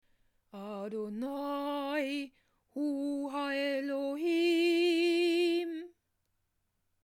Dies wird 7 mal wiederholt - ihr wiederholt die Worte jeweils nach der Kantorin.